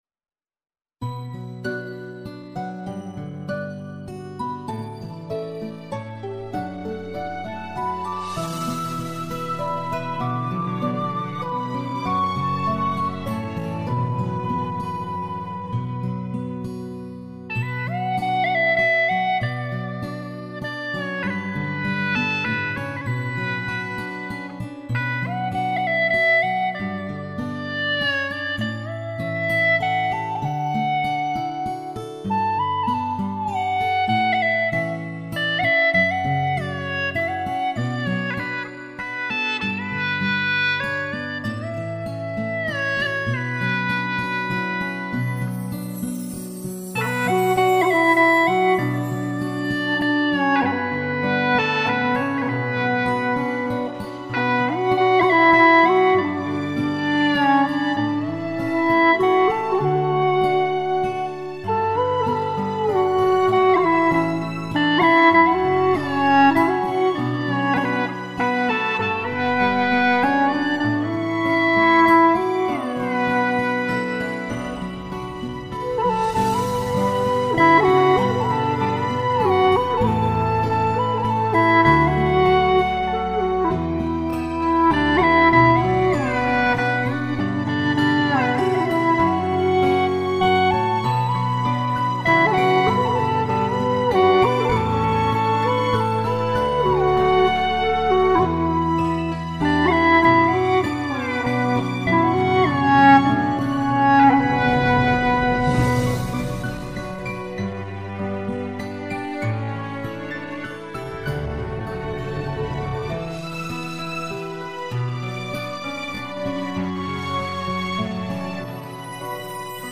调式 : D 曲类 : 古风
【大小D合奏】 我要评论